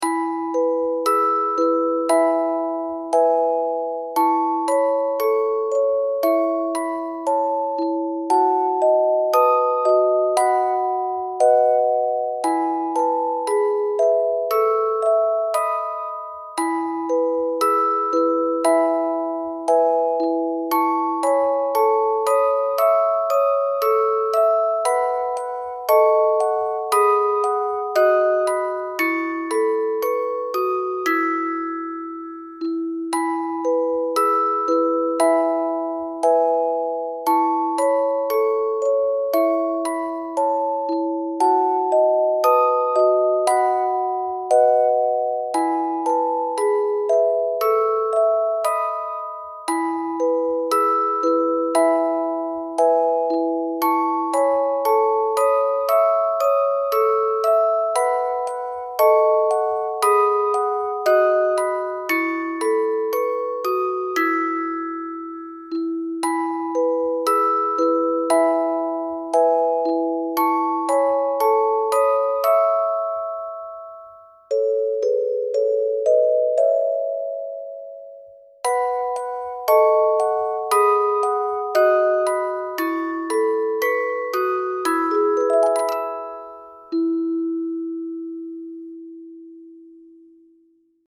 とても可愛らしい曲なので気に入りました。